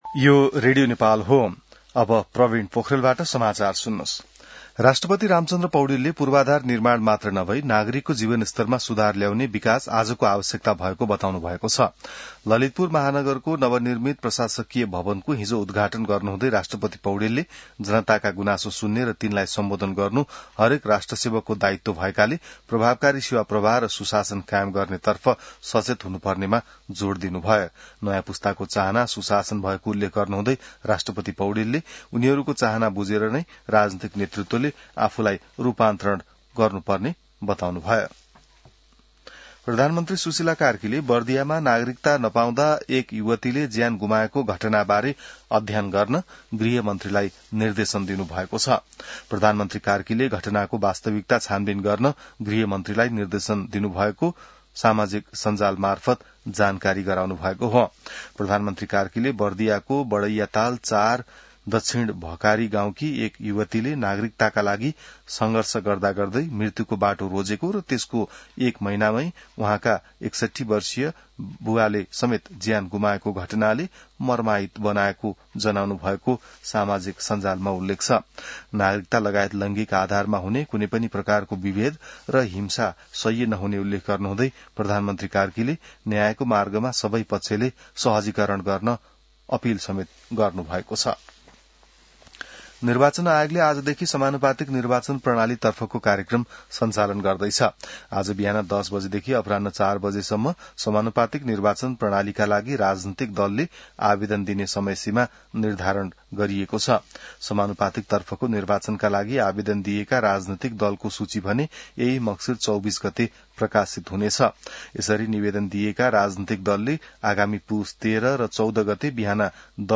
An online outlet of Nepal's national radio broadcaster
बिहान ६ बजेको नेपाली समाचार : २८ असार , २०८२